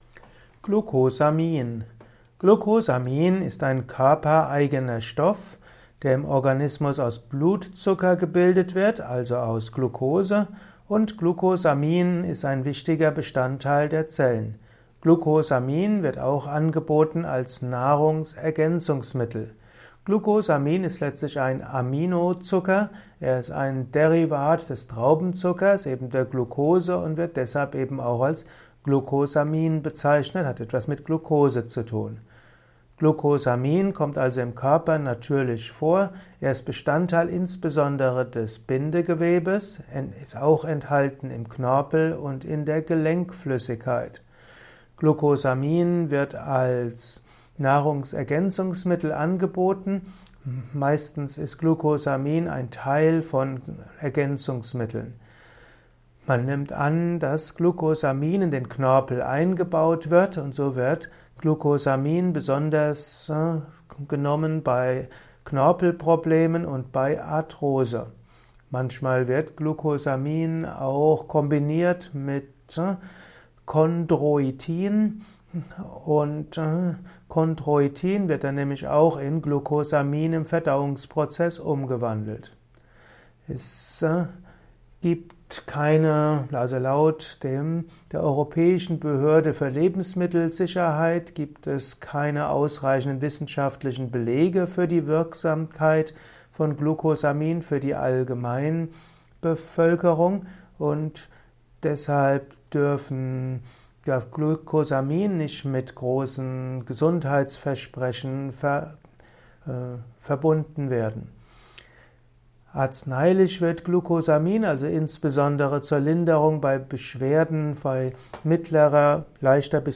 Ein Kurzvortrag über Glukosamin